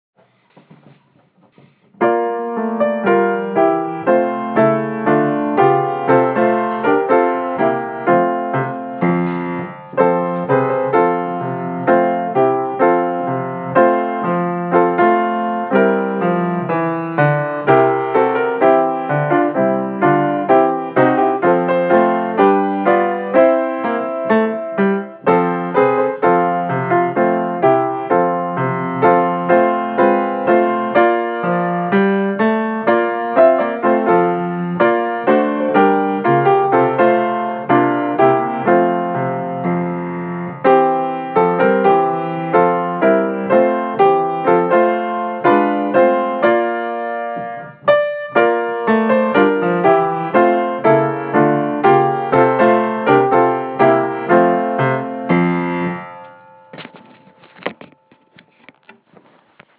Fight Song (Instrumental Full Length Version)
CHC-fight-song_instrumental_8.31.15.wav